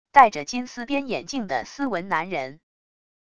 带着金丝边眼镜的斯文男人wav音频